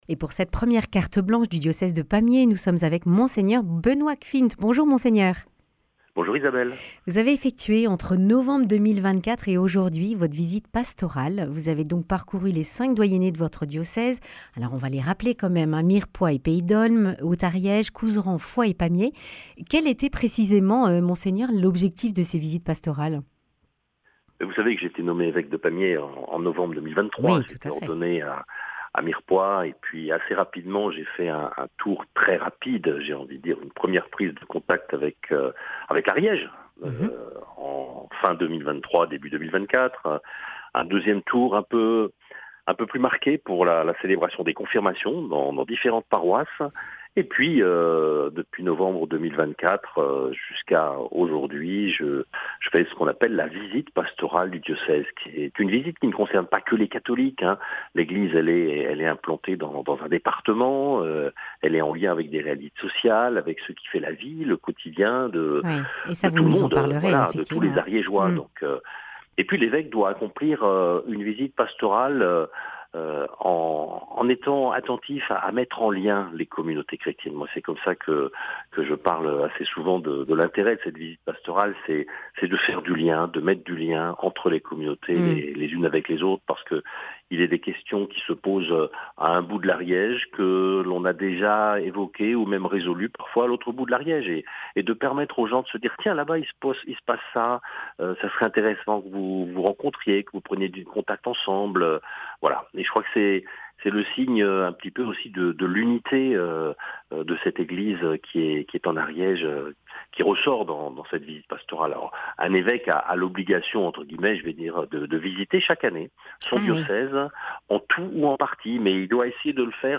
Carte blanche de Mgr Benoit Gschwind, évêque de Pamiers